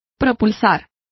Complete with pronunciation of the translation of propel.